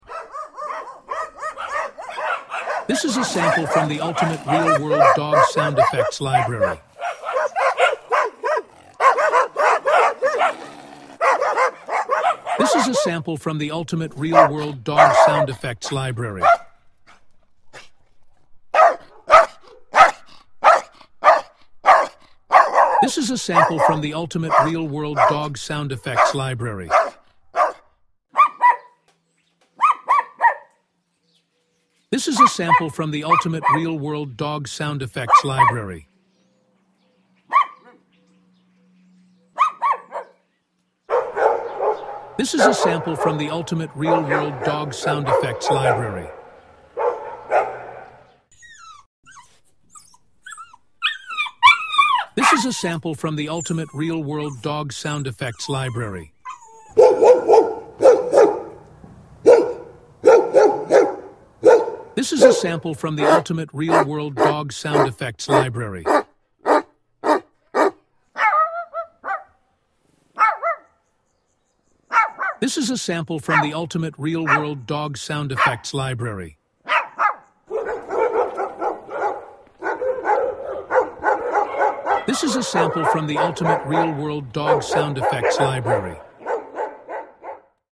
Ultimate Real-World Dog Sound Effects – Commercial Pack
Get 174 authentic dog sound effects recorded over a decade in real-world locations. Each sound features natural barks, growls, howls, and whines, captured indoors and outdoors for realistic acoustics and organic character.
Tascam DR-100MKIII, Zoom H2essential (32-bit float), Zoom H5 & H4n, Yamaha POCKETRAK C24
Short demo of sounds
Ultimate-real-world-dog-sound-effects-library.mp3